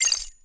emit_keys_01.wav